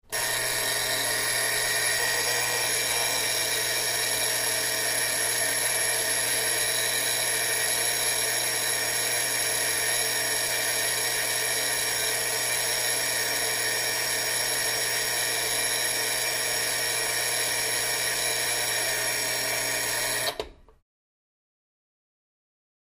Alarm Oven Timer Old 3; On Quick, Buzzer Type Runs Ratty, Off